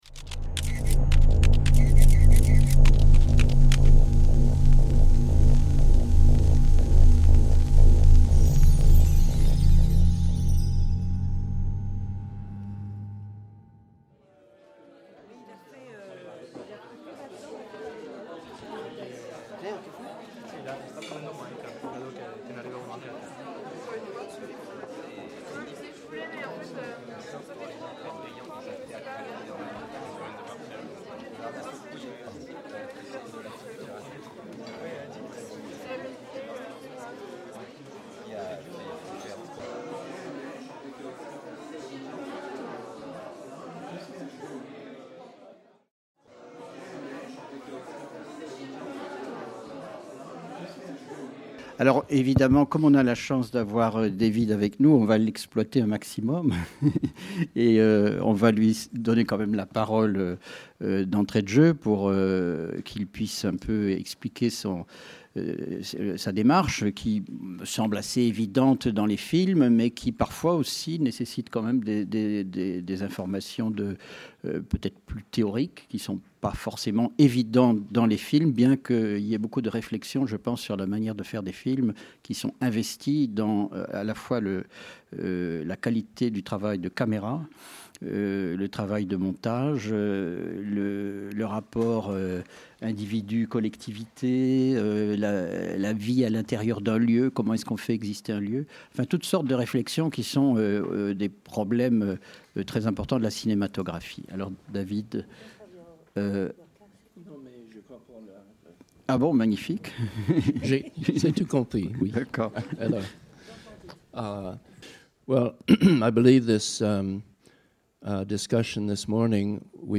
1 - Table ronde (VO) - Hommage à David MacDougall | Canal U